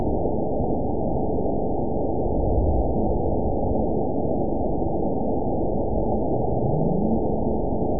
event 917166 date 03/22/23 time 17:57:22 GMT (2 years, 1 month ago) score 9.28 location TSS-AB01 detected by nrw target species NRW annotations +NRW Spectrogram: Frequency (kHz) vs. Time (s) audio not available .wav